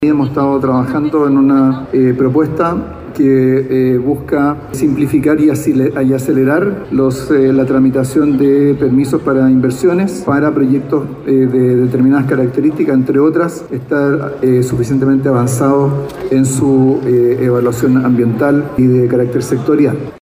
La noticia la precisó durante su intervención en la segunda jornada de la Semana de la Construcción.